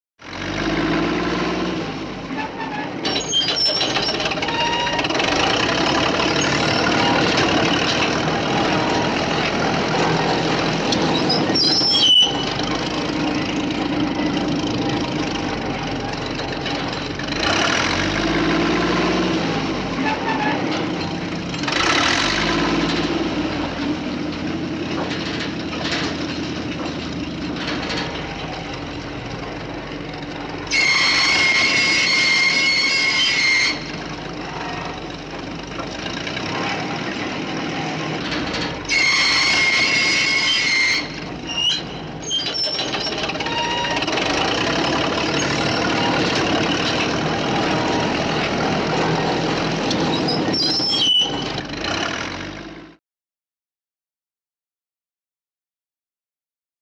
Звук скрипа и движения металла во время работы бульдозера